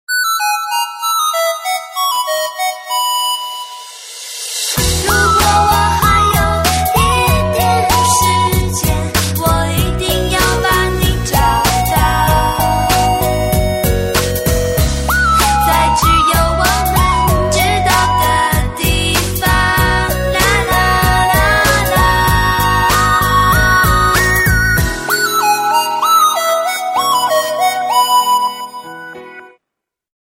. SMS hangok .